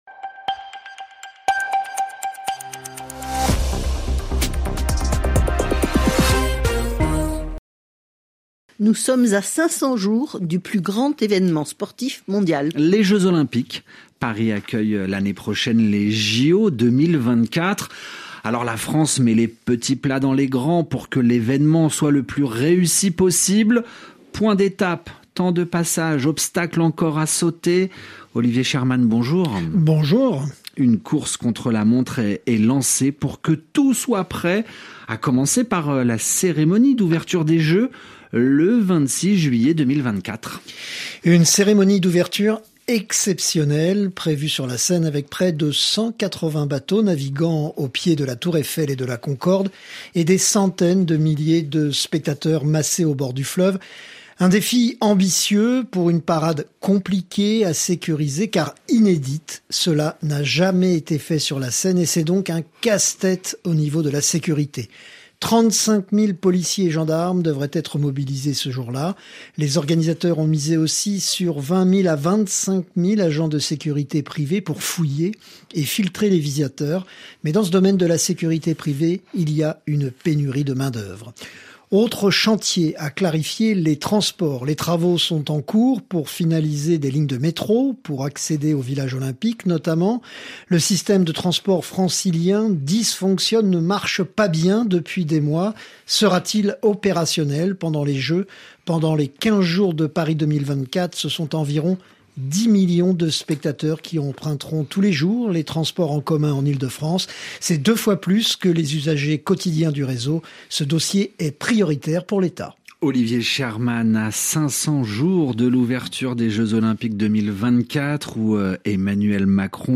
Extrait du Journal en français facile du 13/03/2023 (RFI)